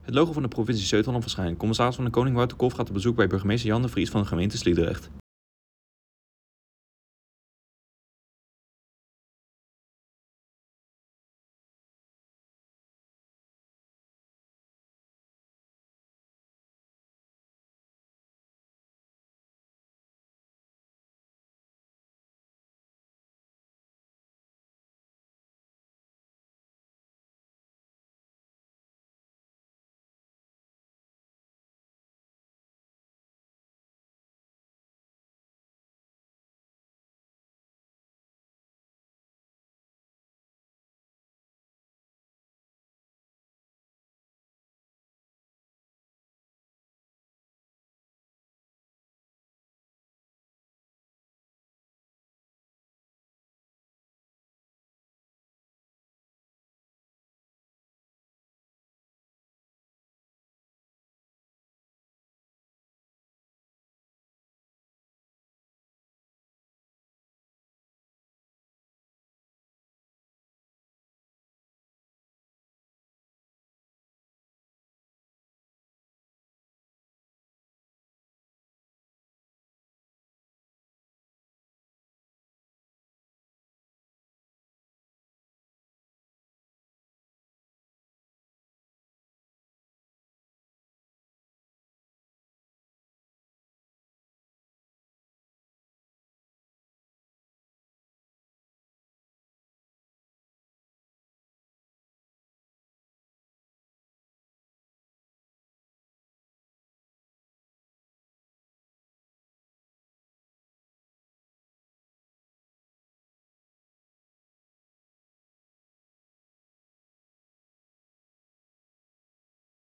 CdK in gesprek met burgemeester Sliedrecht